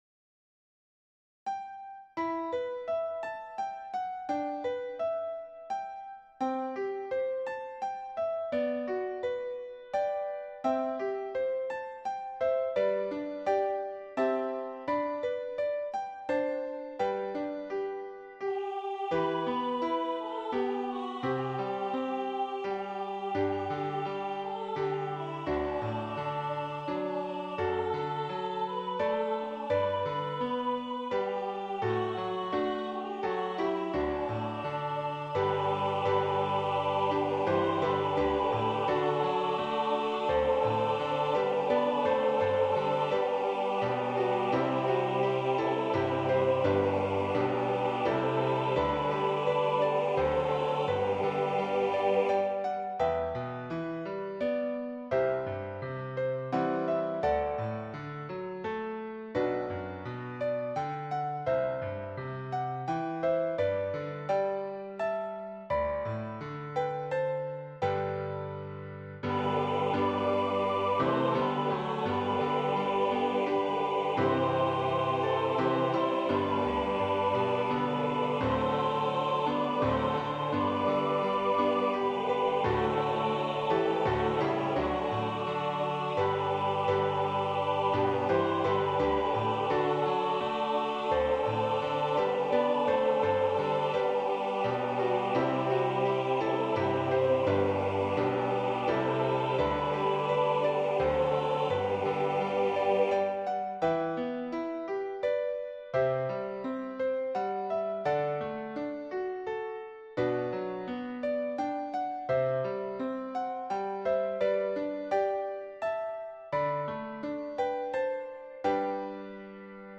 SATB
Voicing/Instrumentation: SATB We also have other 79 arrangements of " I Need Thee Every Hour ".
Choir with Soloist or Optional Soloist